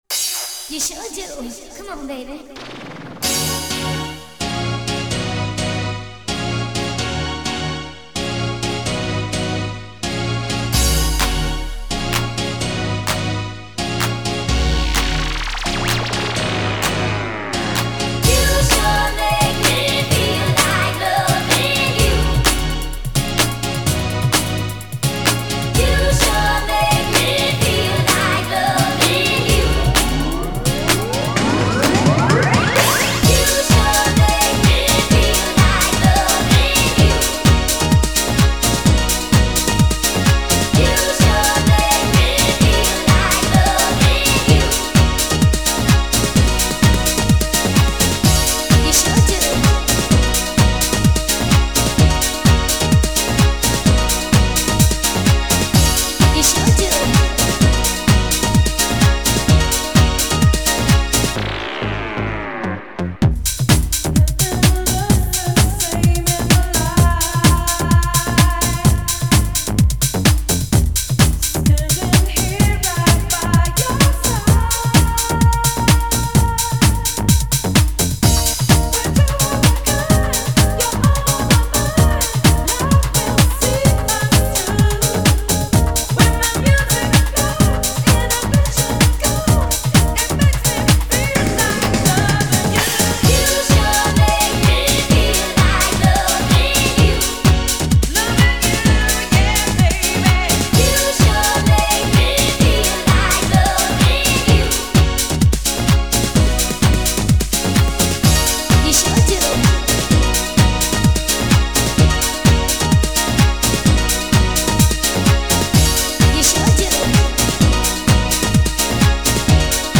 white opera singer